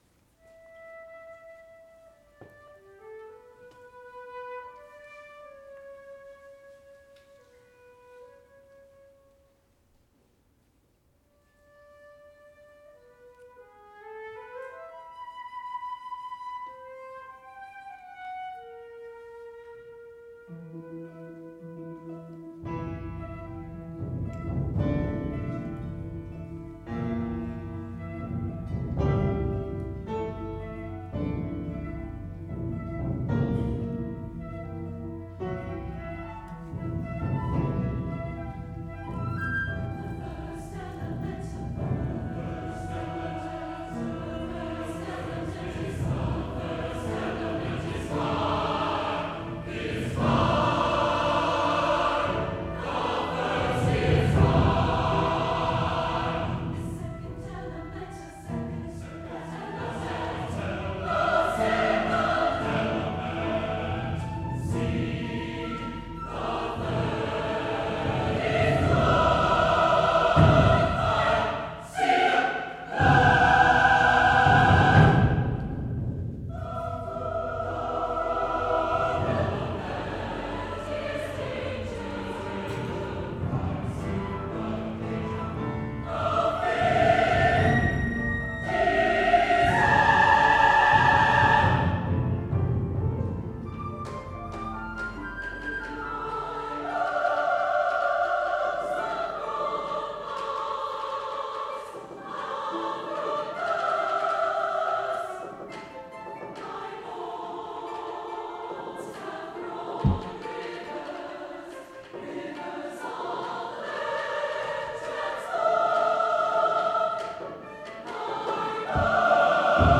Rhythmic, driving, and exciting.
SATB, flute, bass drum, harp, piano